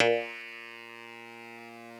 genesis_bass_034.wav